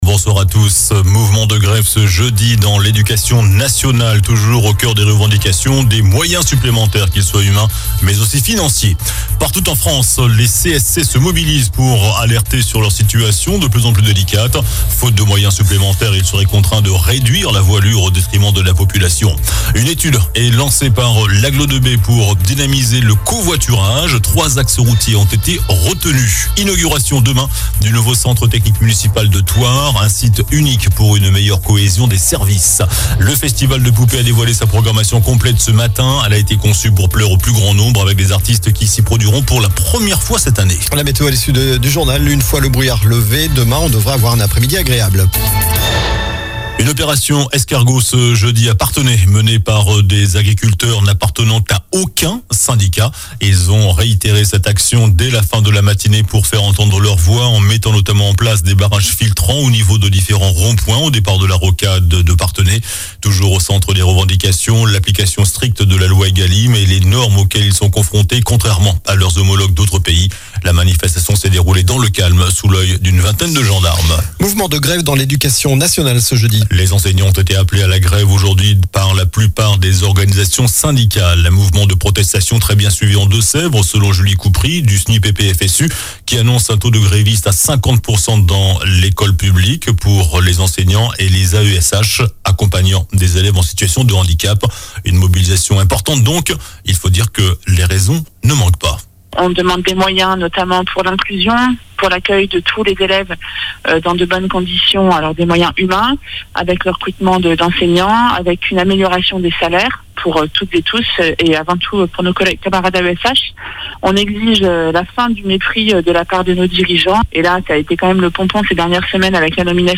JOURNAL DU JEUDI 01 FEVRIER ( SOIR )